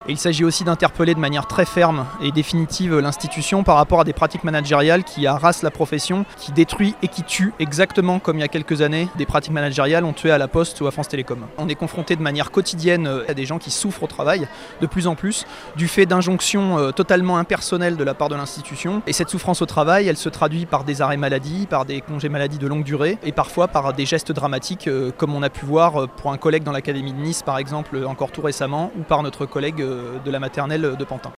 Hommage et indignation ce jeudi à Bobigny en Seine-Saint-Denis.
Reportage Sud Radio